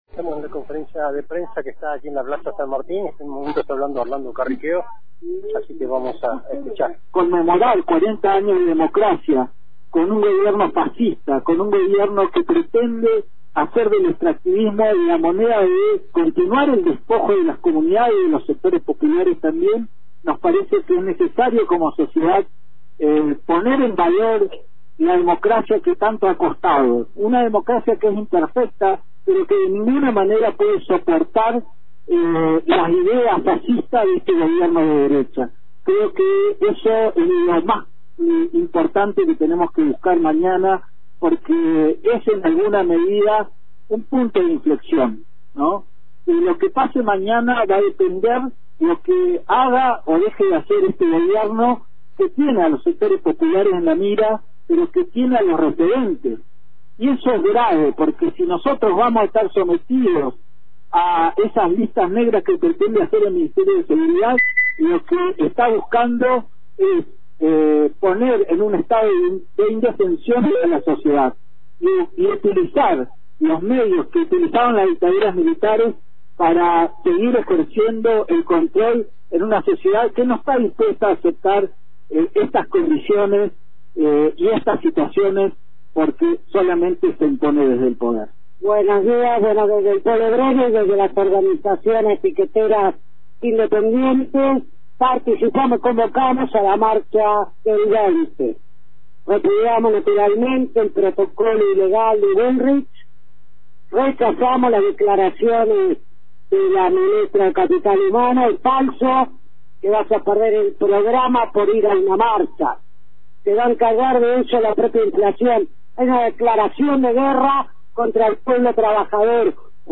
Distintas Organizaciones Sociales y Piqueteras brindaron hoy una conferencia de prensa en la plaza San Martín de Roca, donde el principal tema fue las medidas de ajuste que implementa el gobierno de Javier Milei.